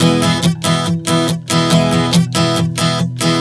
Strum 140 E 03.wav